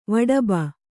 ♪ vaḍaba